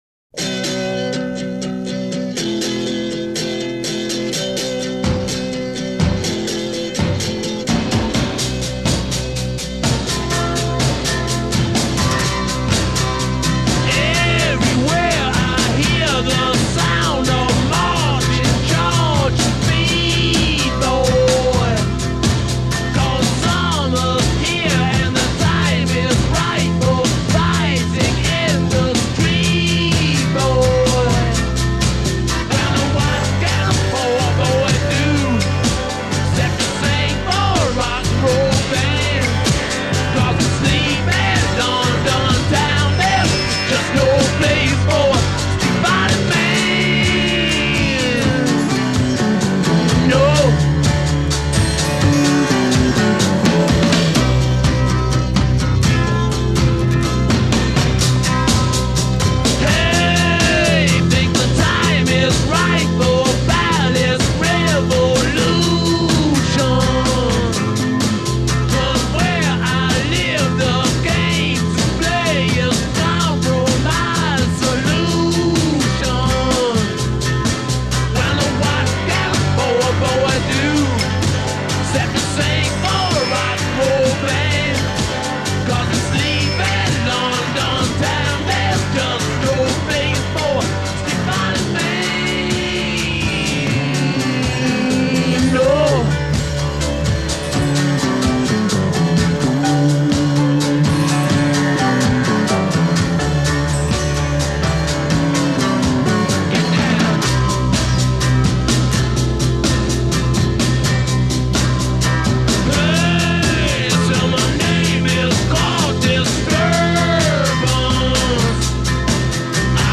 Recorded 13-18 May 1968 at Olympic Studios, London
Refrain 7+ 4 Double-tracked vocal with second unison vocal;
add piano, tambura, & clavé; allow 4 measures of fill.   b
Coda 8+ Continue refrain material and fade.